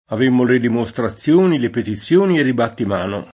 av%mmo le dimoStraZZL1ni, le petiZZL1ni ed i battim#no] (id.)